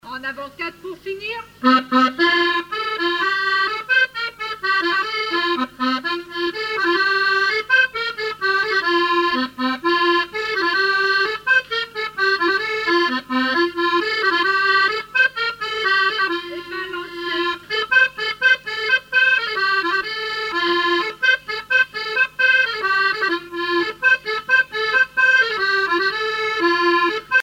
Grand'Landes
danse : quadrille : avant-quatre
Musique du quadrille local
Pièce musicale inédite